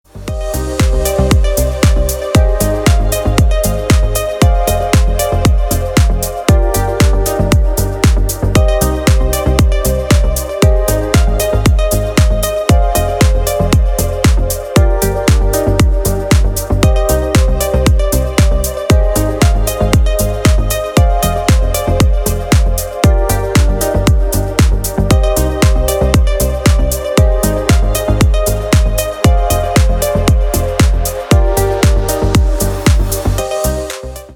Нарезки музыки без слов
Нарезка без слов на вызов